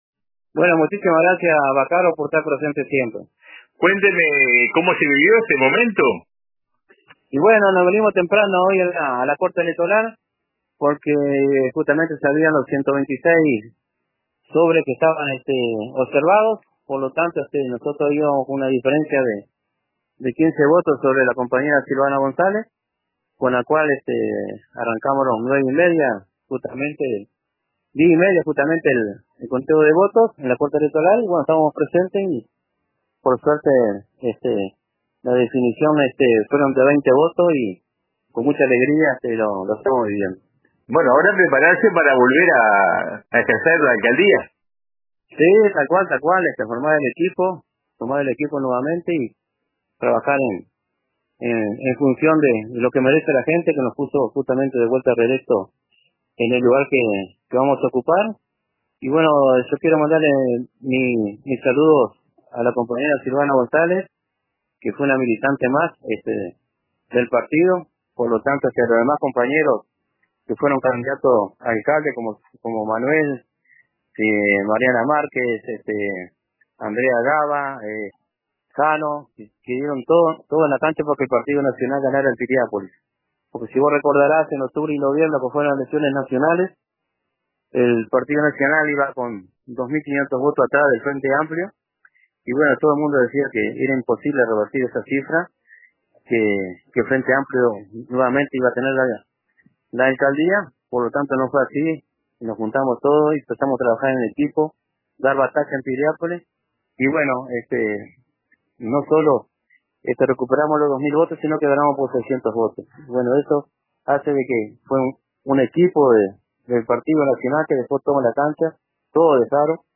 En su camino hacia Piriápolis, Graña se detuvo unos minutos para atender el llamado de Radio RBC.